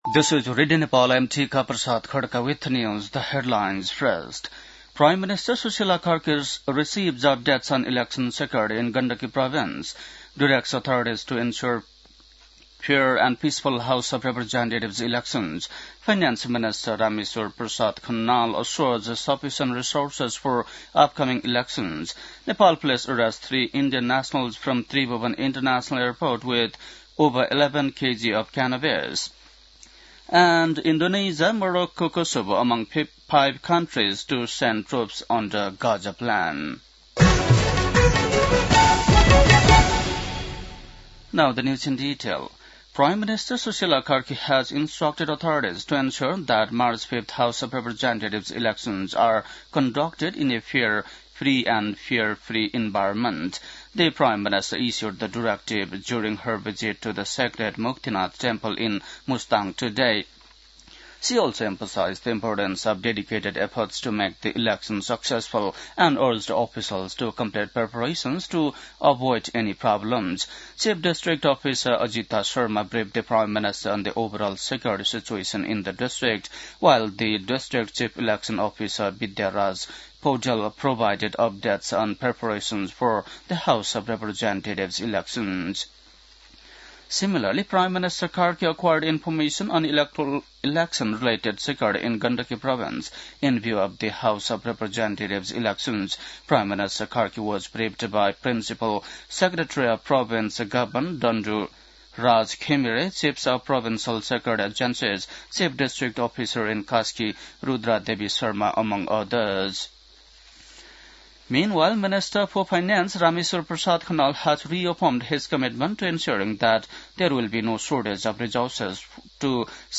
बेलुकी ८ बजेको अङ्ग्रेजी समाचार : ८ फागुन , २०८२
8-pm-hindi-news-11-08.mp3